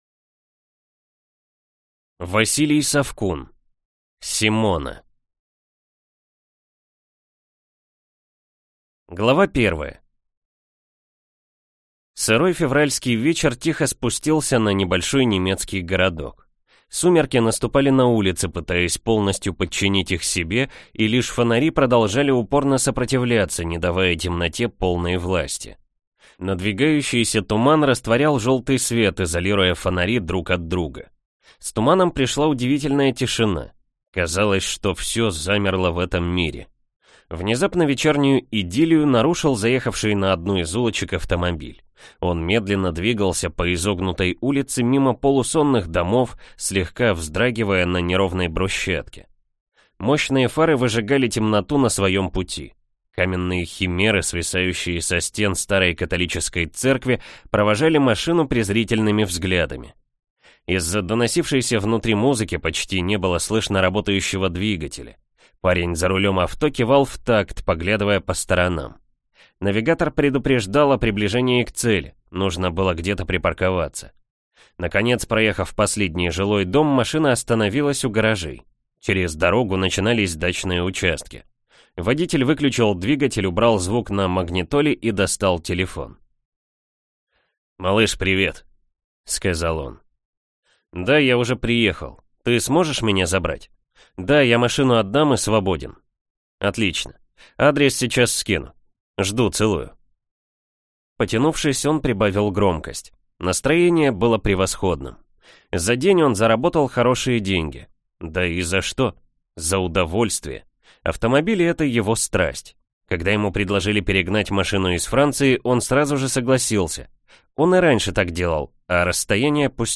Аудиокнига Симона | Библиотека аудиокниг
Прослушать и бесплатно скачать фрагмент аудиокниги